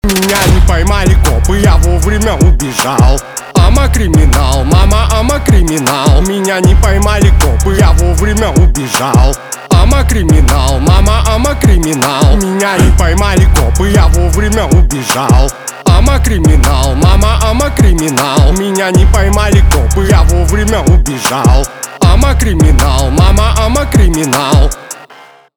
русский рэп , жесткие , пацанские , битовые , басы